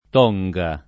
tonga [ t 0jg a ]